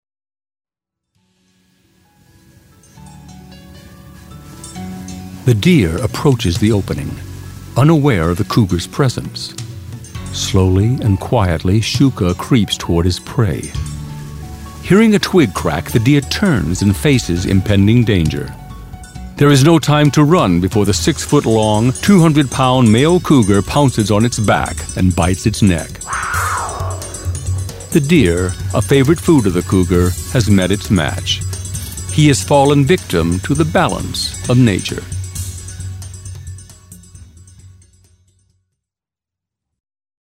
USA. All-American authority and highly versatile characters. 'Toon Pro.